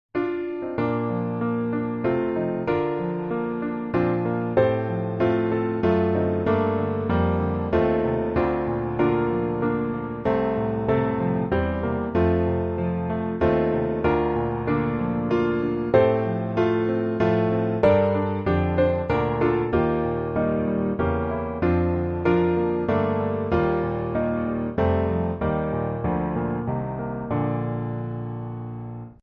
Hymn: O Lord!
Piano Hymns
C Major